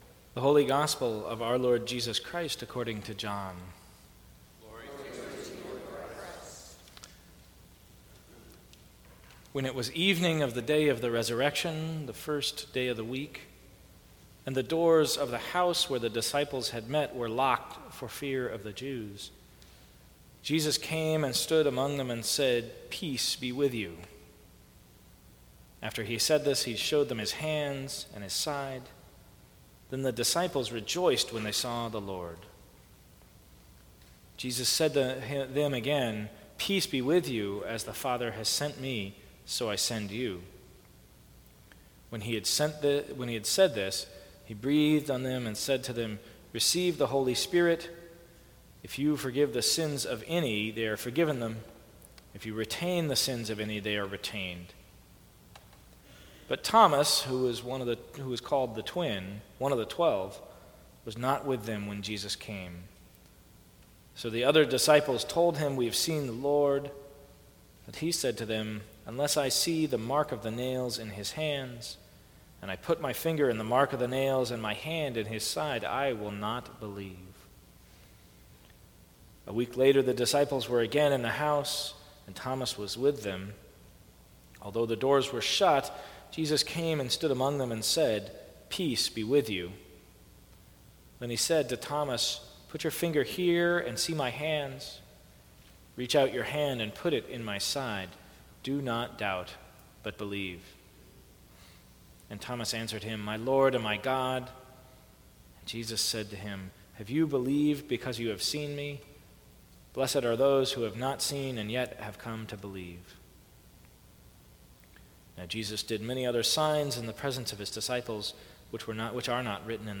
Sermons from St. Cross Episcopal Church Resurrection and Forgiveness Apr 23 2017 | 00:10:47 1x 00:00 / 00:10:47 Subscribe Share Apple Podcasts Spotify Overcast RSS Feed Share Link Embed